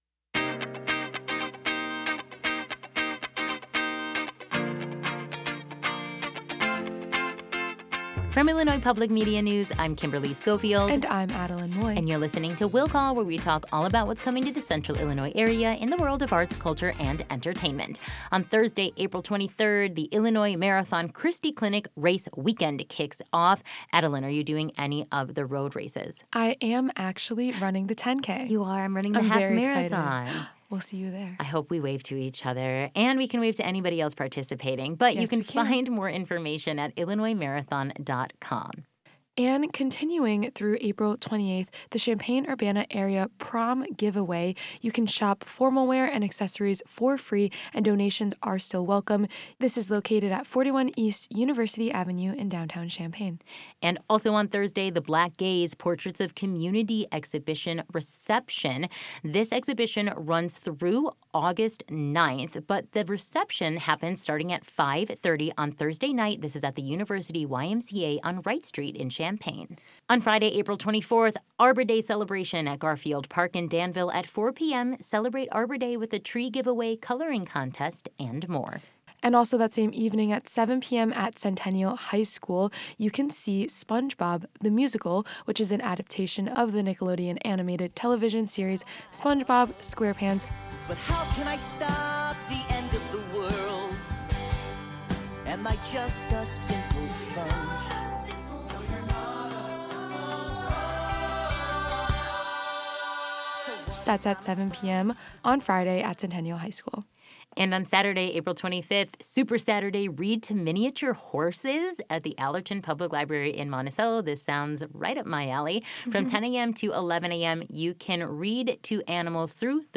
talk about weekend events on IPM News AM 580 and FM 90.9